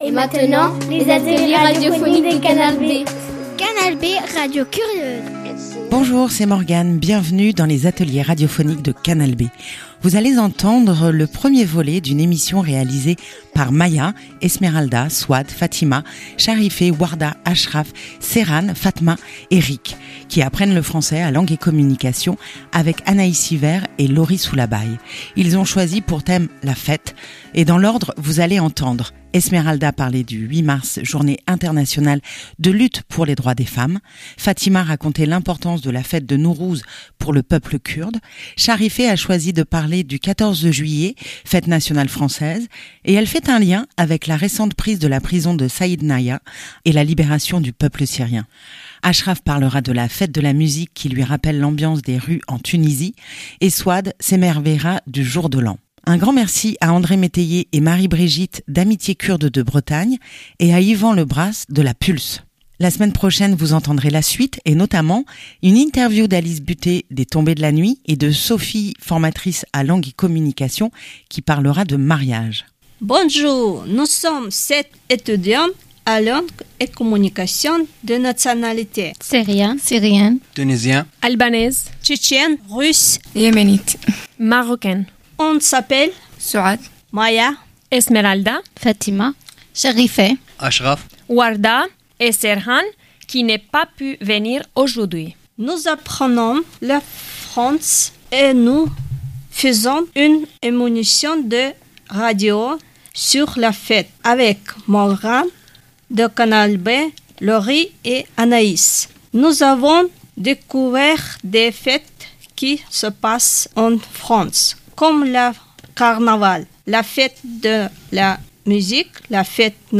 Ateliers radiophoniques | Langue et Communication - La fête - Episode 1